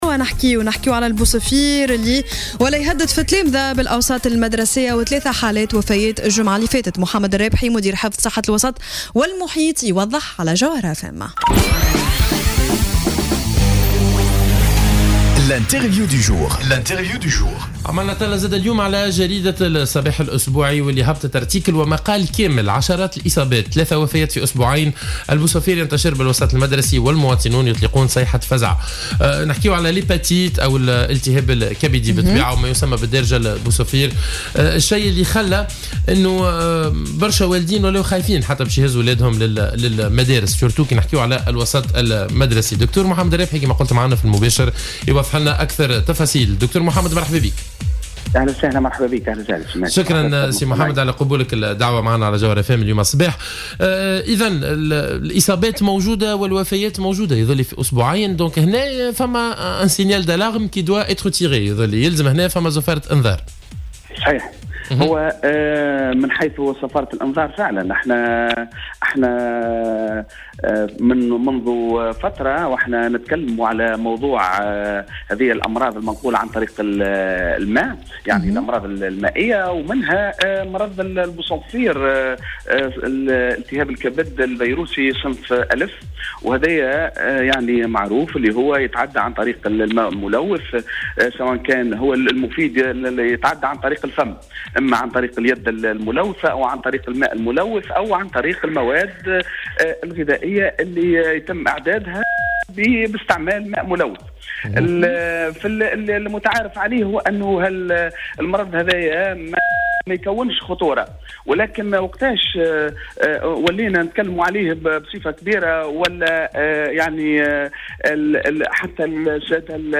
وقال في برنامج "صباح الورد" إنه لوحظ انتشار مرض البوصفير (الالتهاب الكبدي) في المناطق التي تفتقد الماء الصالح للشرب والتي تنعدم بها أيضا خدمات الصرف الصحي وهي كلها عوامل أدت الى انتشار هذا المرض مؤكدا أنه عادة ما يكون هذا المرض غير خطير في مراحله الأولى.